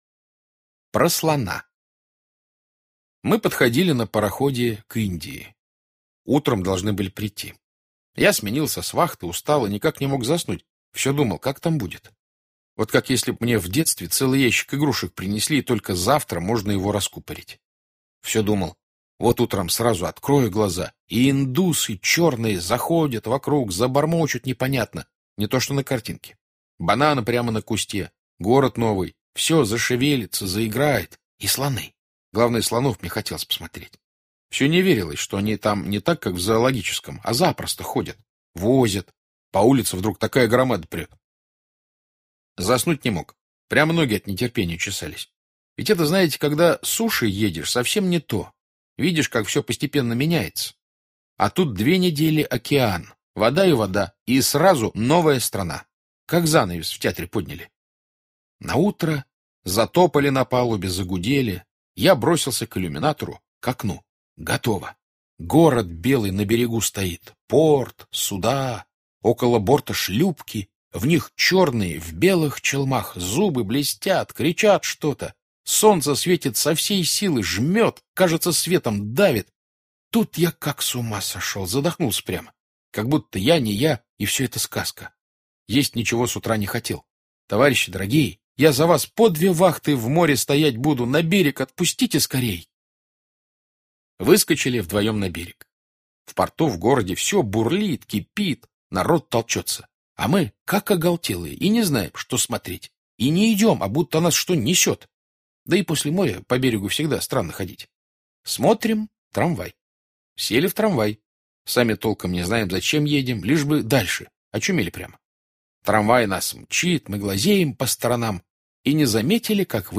Про слона - аудио рассказ Житкова Б.С. Автор приплыл на корабле в Индию.